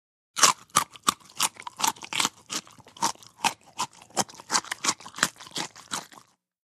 DINING - KITCHENS & EATING CARROT: INT: Biting into & chewing a carrot.